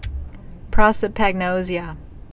(prŏsə-păg-nōzē-ə, -zhə, prō-)